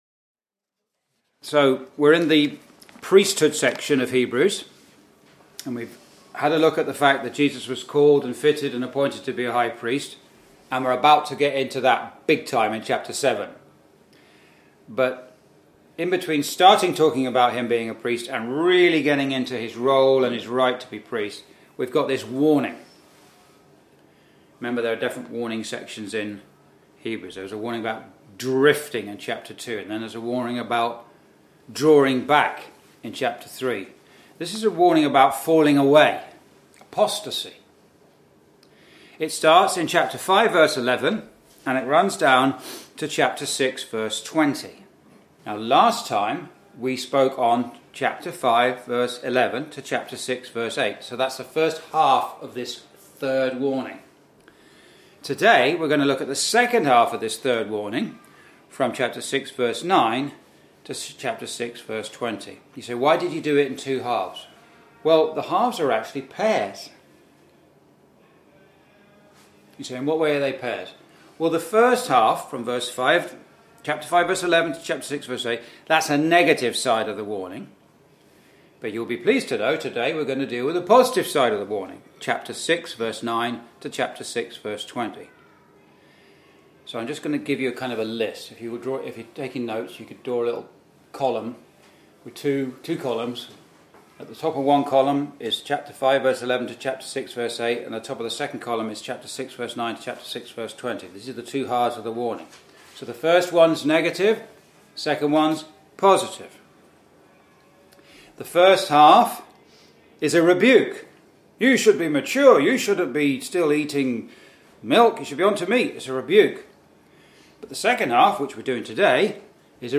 (Message preached in Chalfont St Peter Gospel Hall, 2024)
Verse by Verse Exposition